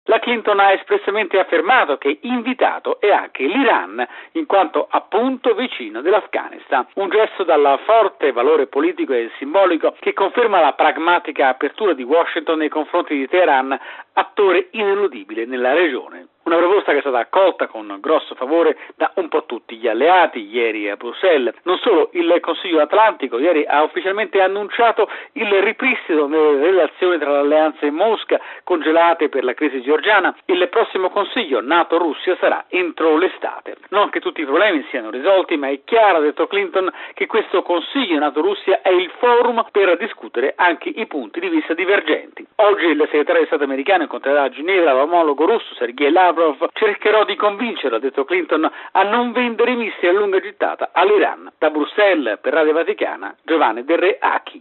Da Bruxelles